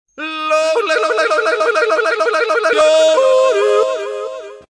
Jodel
Jingle-21-Jodel 01-.mp3